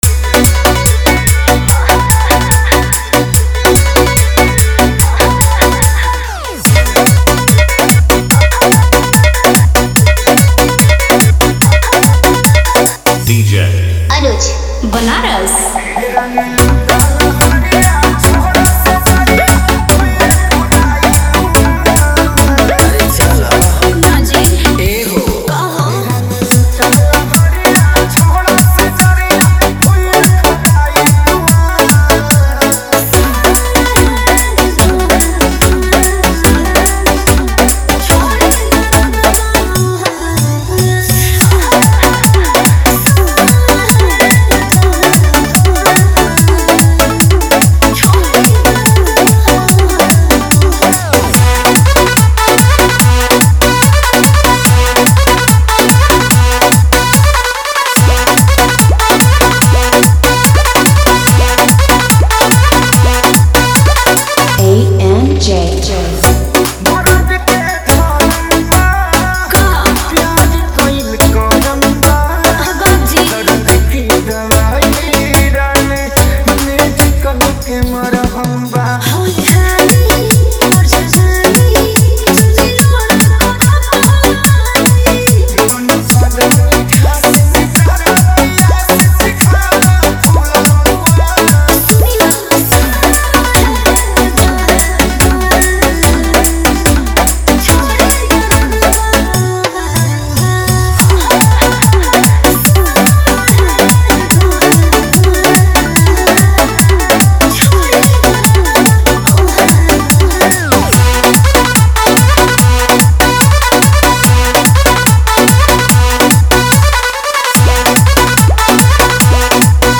New Bhojpuri Dj Remix Song 2025